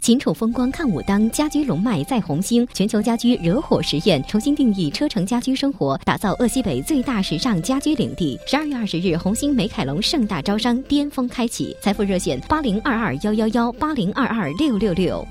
女国23_广告_家居家电_红星美凯龙_自然.mp3